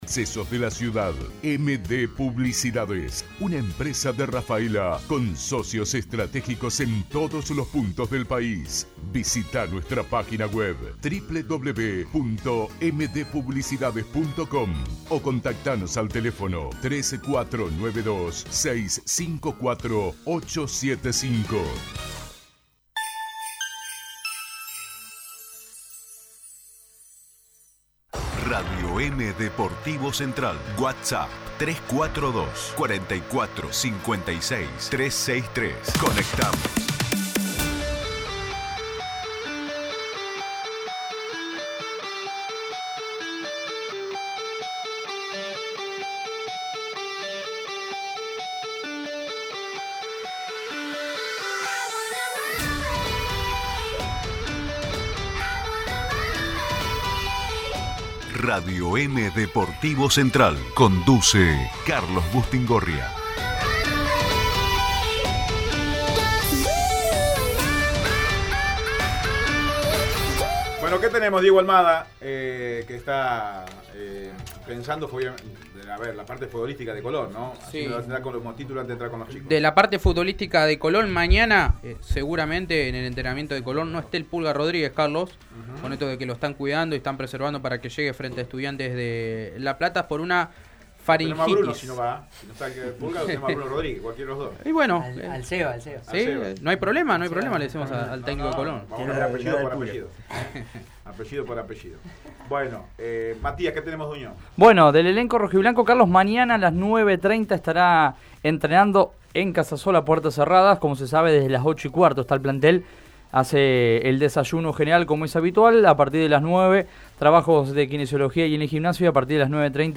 Los Búhos en los estudios de Radio Eme | Radio EME